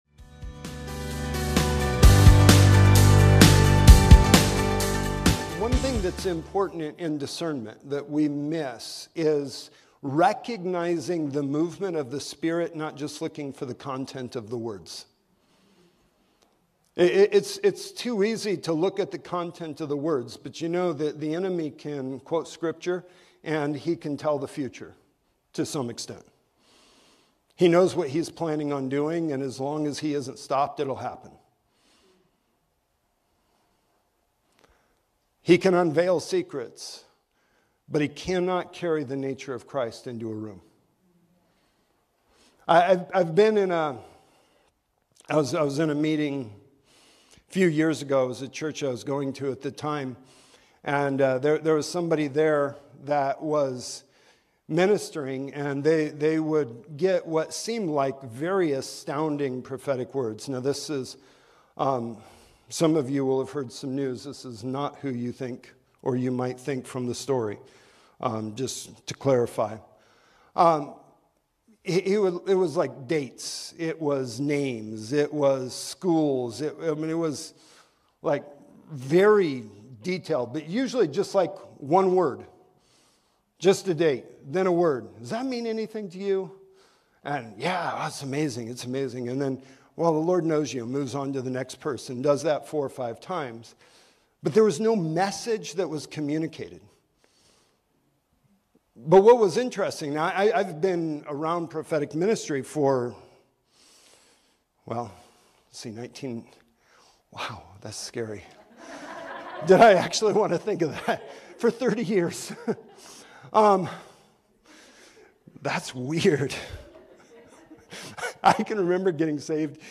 In this clip from one of our Emerge Transformation School intensives